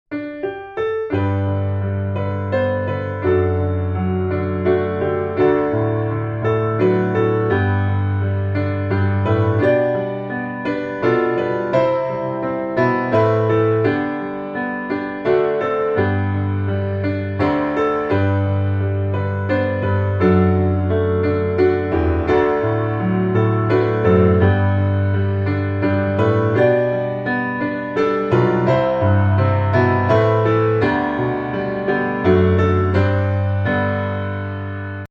G Majeur